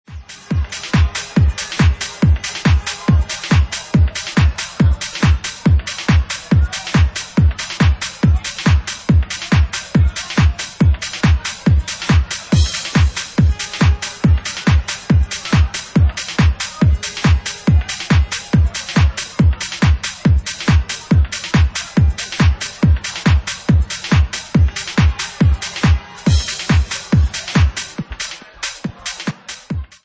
Bassline House at 140 bpm